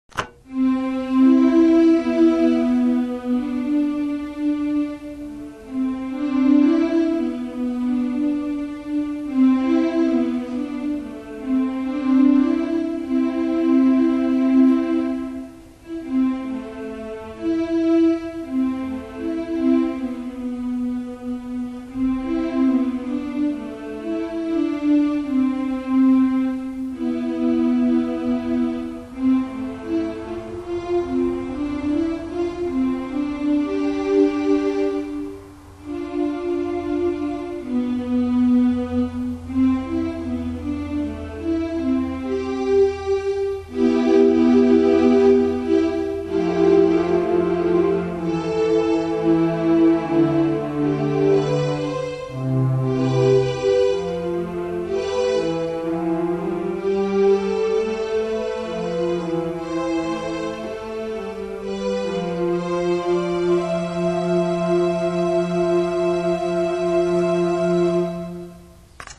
I cleared my mind, changed the piano setting to "strings" (have no idea why) and placed my hands over the keys and trusted.
Instead, I bought a handheld digital recorder at Radio Shack.
Forgive the lack of a professional recording because all I do is put the recorder in the room and hit "record." Sometimes, in the background, you might hear cars passing outside my window, dogs barking or me shifting in my seat. When the musical samples seem to end abruptly, they represent the "session" in its entirety - meaning, when my hands stop, they stop.